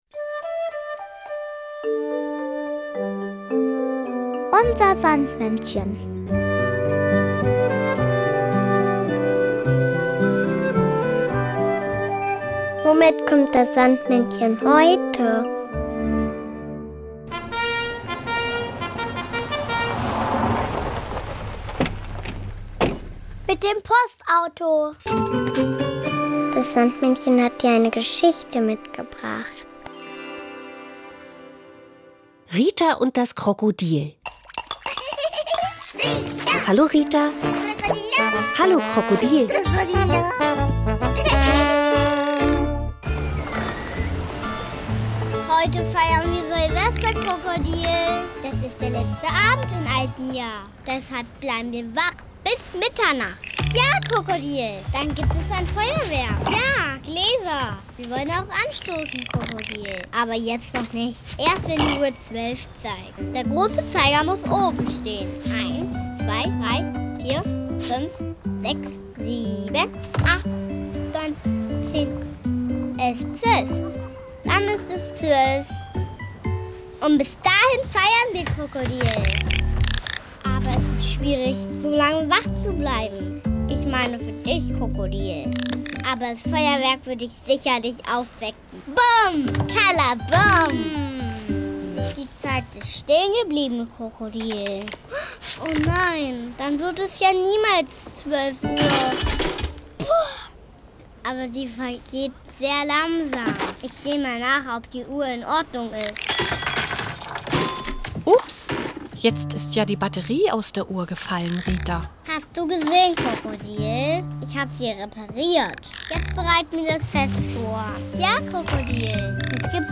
das Kinderlied "Rabatz" von Nena.